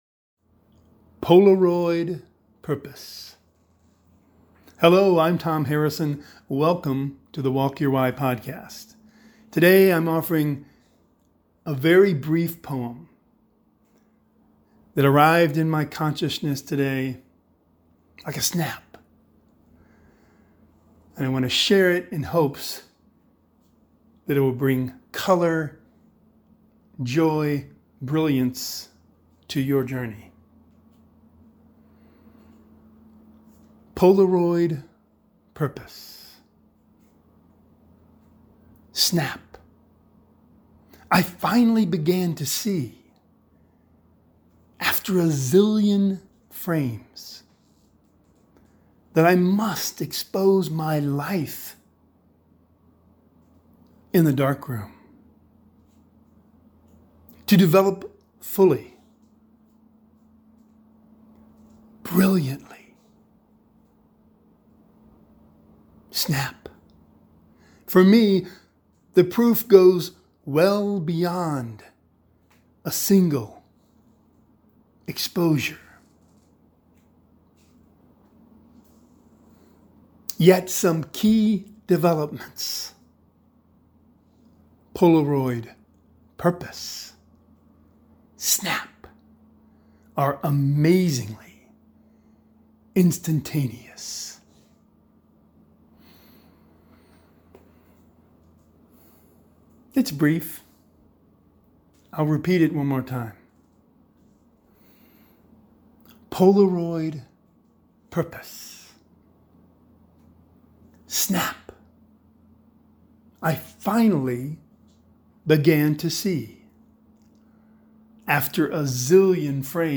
Please join me, as I walk (and talk) my way home from my weekly meditation group along backroads and trails.